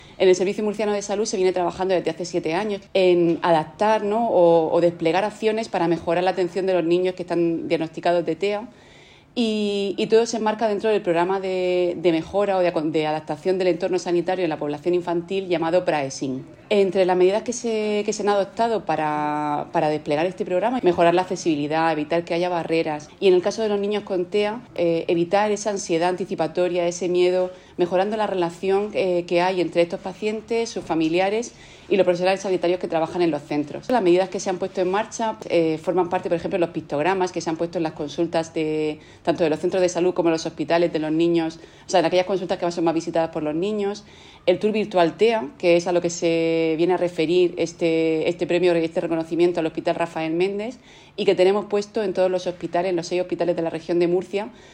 Sonido/ Declaraciones de la gerente del Servicio Murciano de Salud, Isabel Ayala, durante la presentación de los premios anuales de la asociación Astrade.